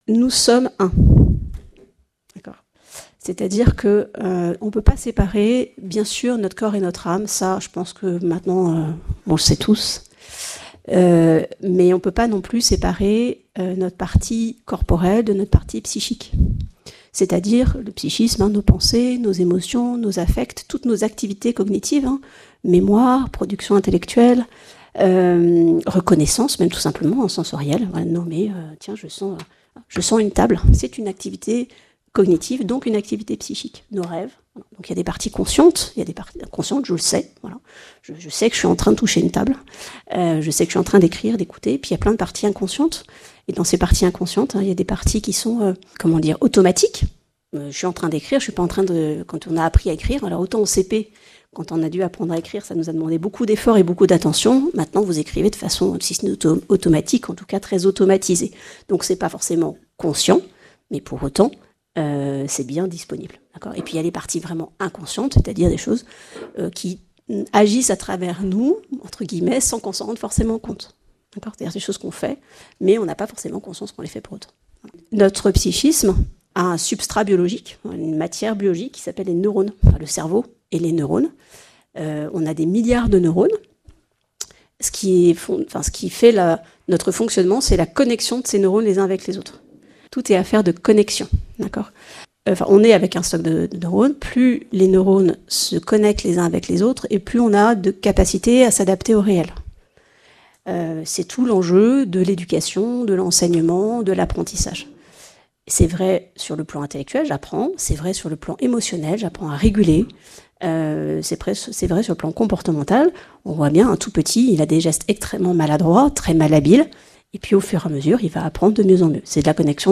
Conférence de la semaine